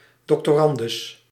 Doctorandus (Dutch pronunciation: [dɔktoːˈrɑndʏs]